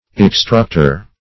extructor - definition of extructor - synonyms, pronunciation, spelling from Free Dictionary Search Result for " extructor" : The Collaborative International Dictionary of English v.0.48: Extructor \Ex*truct"or\, n. [L.]
extructor.mp3